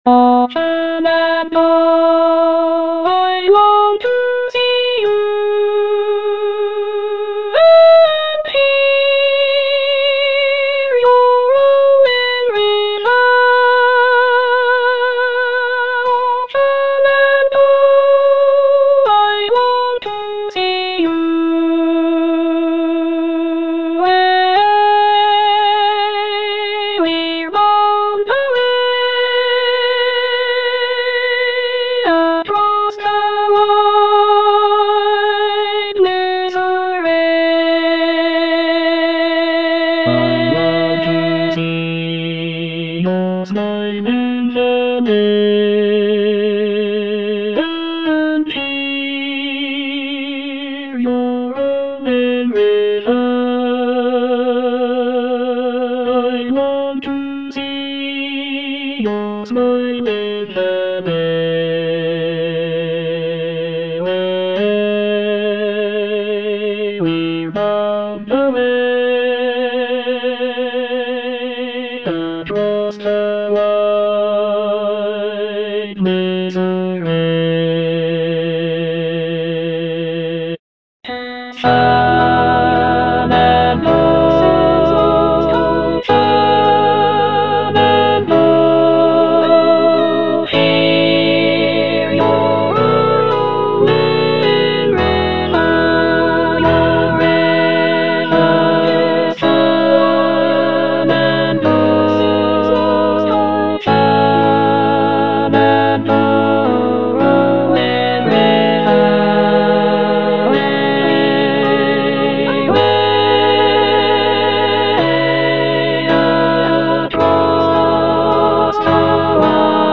Alto II (Emphasised voice and other voices)